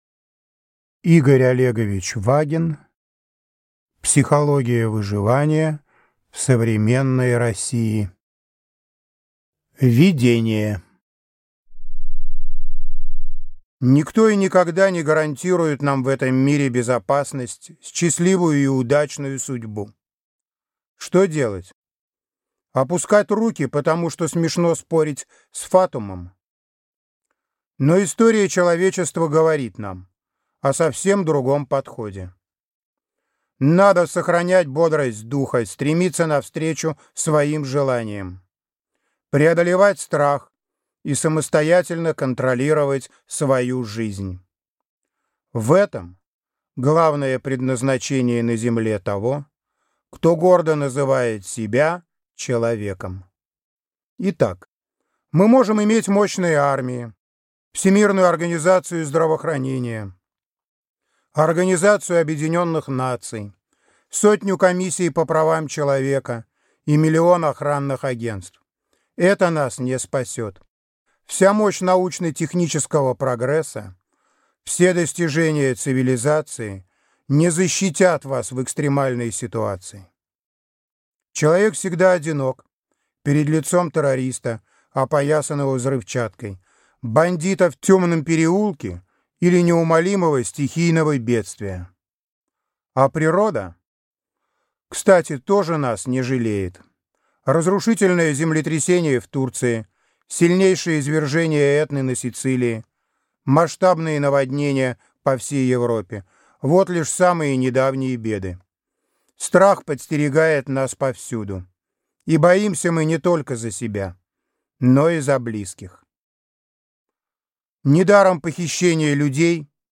Аудиокнига Психология выживания в современной России | Библиотека аудиокниг
Прослушать и бесплатно скачать фрагмент аудиокниги